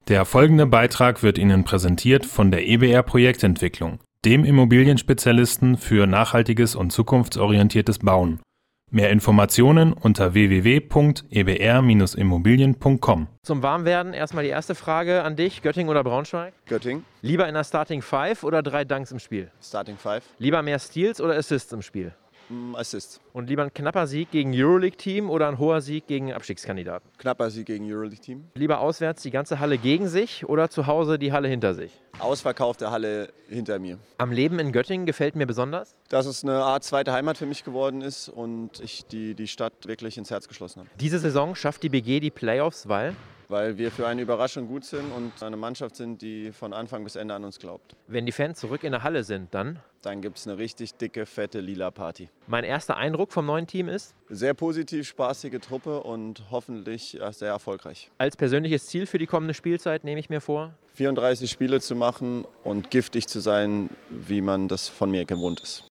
Jetzt im zweiten Teil gibt es noch eine Schnellfragerunde, mit fünf Entweder-Oder-Fragen und fünf offenen Fragen.